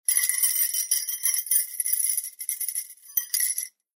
Danza árabe, pulseras de aros de la bailarina 02
agitar
Sonidos: Acciones humanas